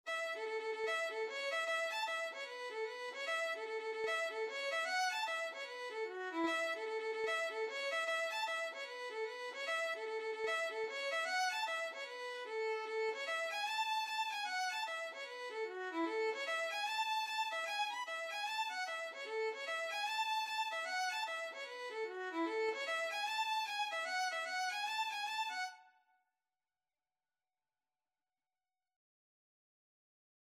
Free Sheet music for Violin
A major (Sounding Pitch) (View more A major Music for Violin )
4/4 (View more 4/4 Music)
Instrument:
Traditional (View more Traditional Violin Music)
Irish